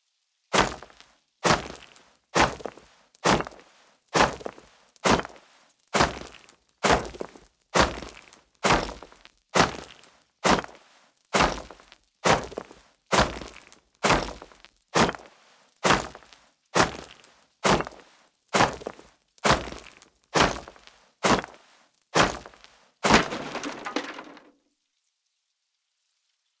Stone Hatchet On Stone Wall